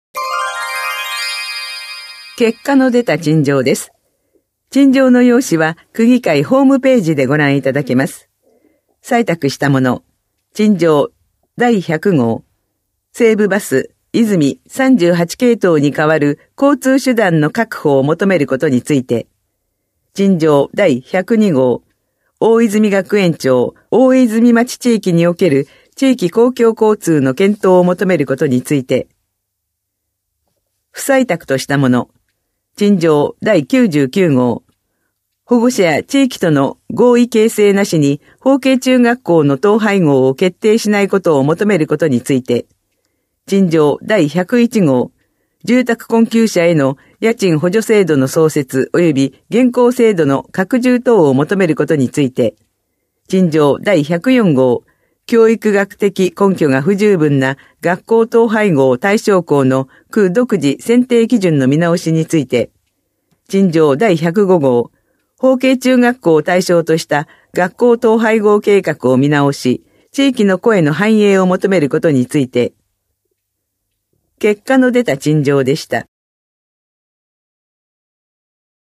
声の区議会だより（音声データ）
練馬区議会では、目の不自由な方のために、デイジーによる「声の区議会だより」を発行しています。